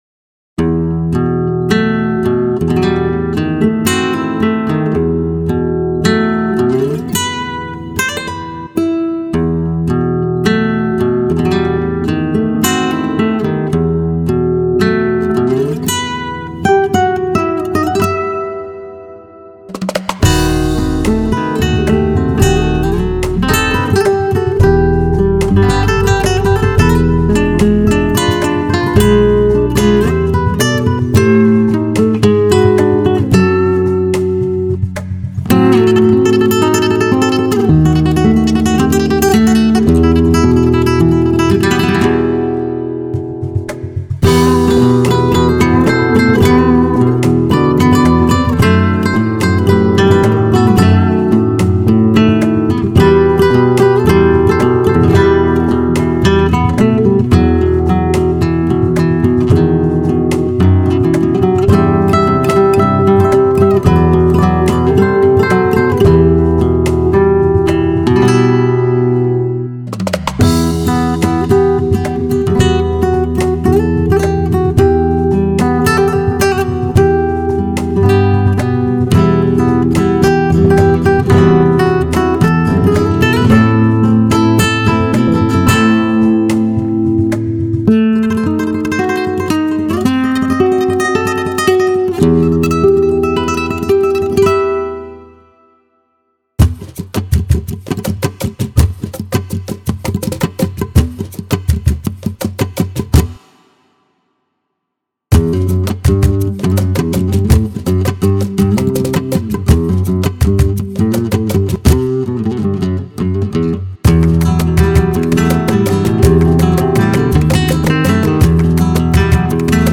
بی کلام